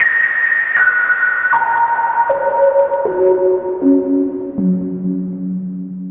synth05.wav